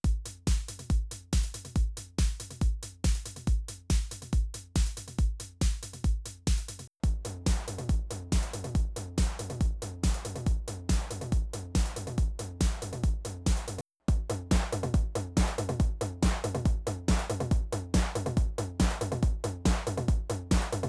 On all of the below, there are four channels from the TR-8: kick, snare/clap, toms/rim, hats.
The FX is EQ’d, with a slight low increase, slight cut at 125, and highs of 8k+ boosted.
Here’s a dry loop, then two loops of the Dark Mouse (Rat) pedal: